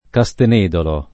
[ ka S ten % dolo ]